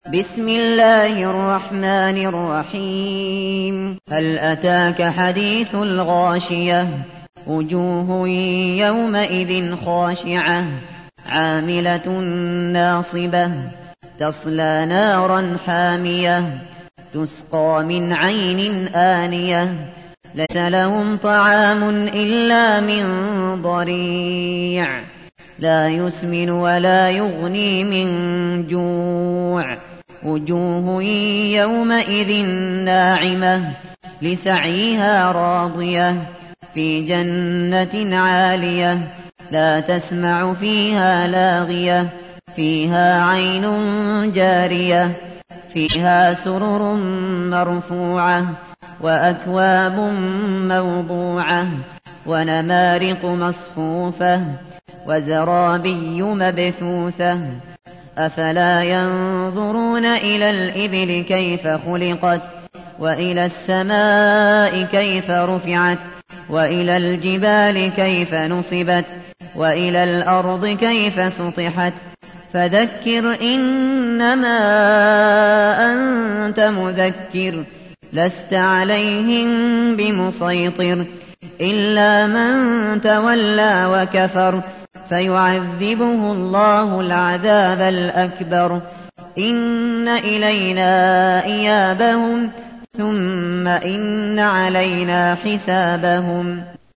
تحميل : 88. سورة الغاشية / القارئ الشاطري / القرآن الكريم / موقع يا حسين